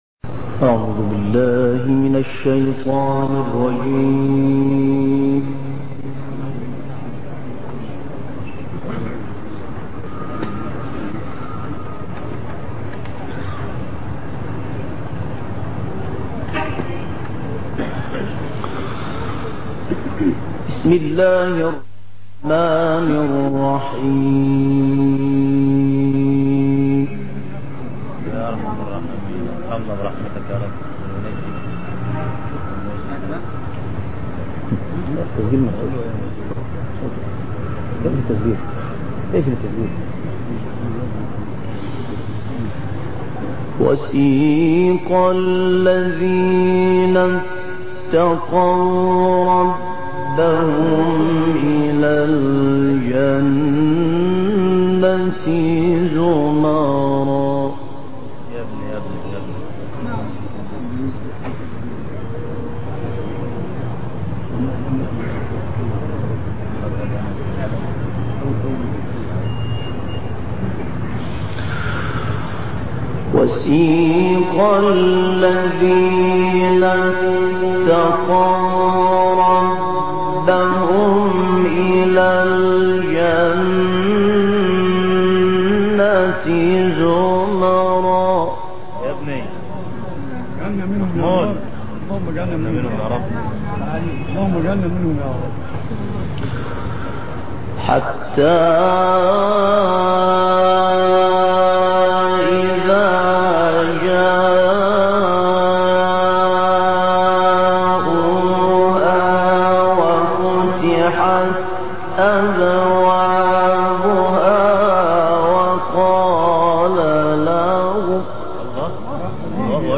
تلاوتی متفاوت
قاری برجسته مصری
با تکرار آیات به صورت فنی، تلاوتی متفاوت و دلنشین را ایجاد کرده است.